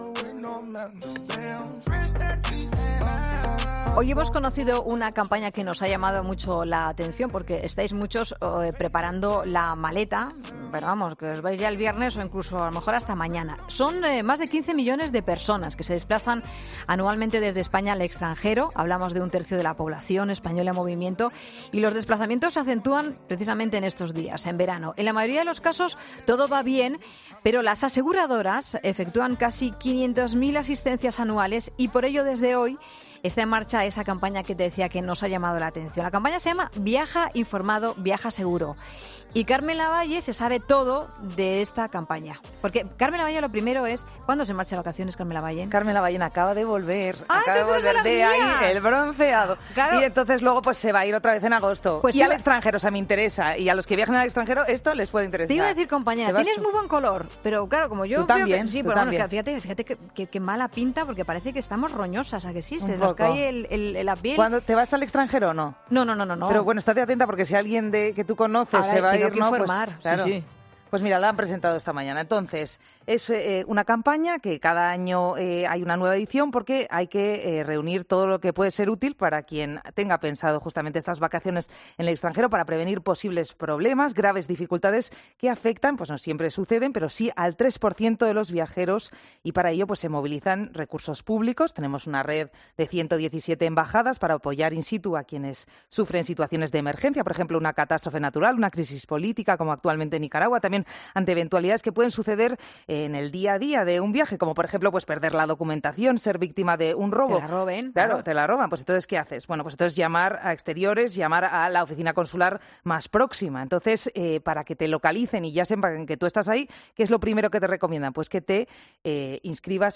Hablamos en 'La Tarde' con